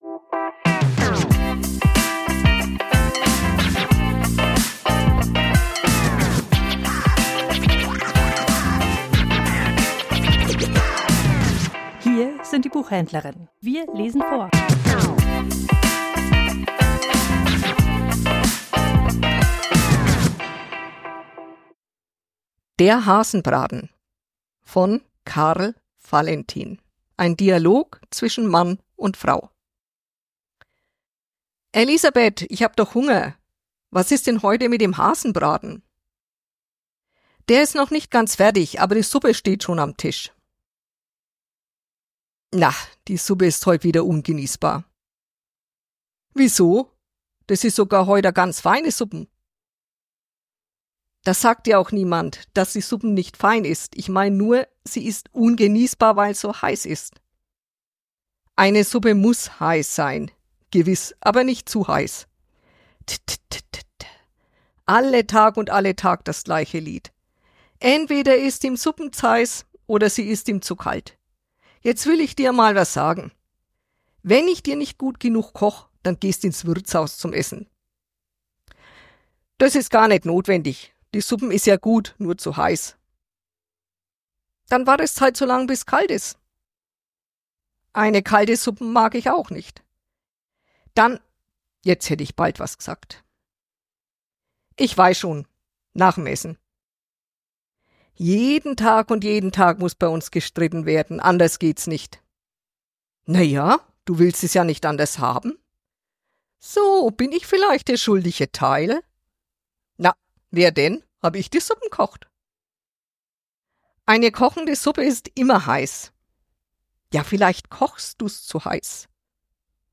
Vorgelesen: Der Hasenbraten